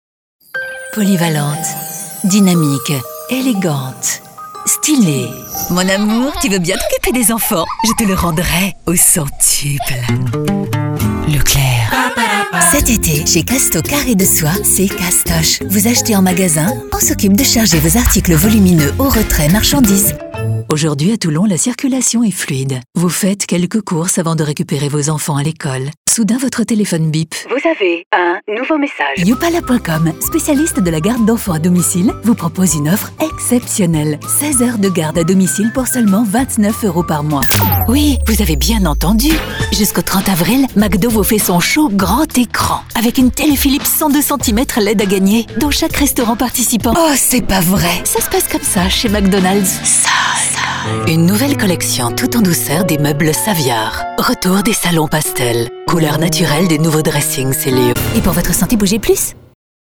Französische Sprecherin. Flexible Stimme.
Sprechprobe: Werbung (Muttersprache):
French native Voice artist with professional Home Studio.